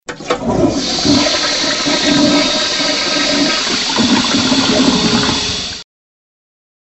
Звук спуска воды в туалете 5 вариантов